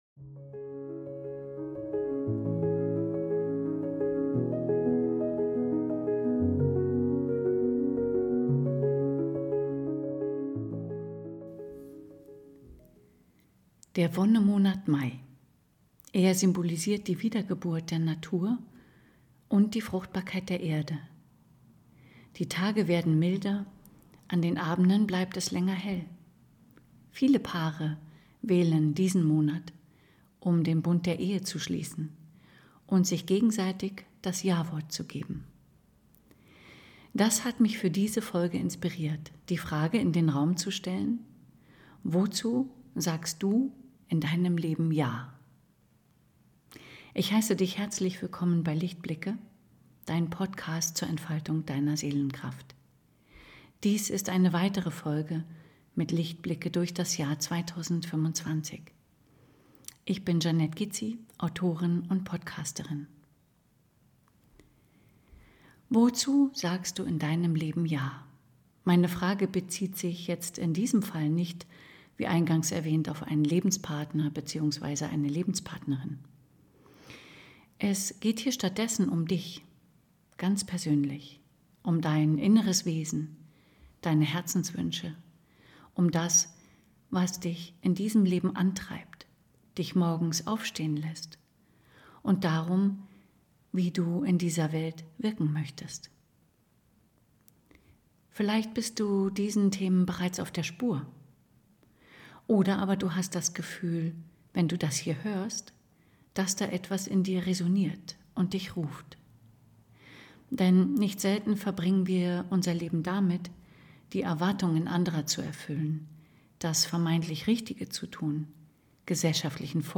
Wozu_sagst_du_ja_mit_intro_outro.mp3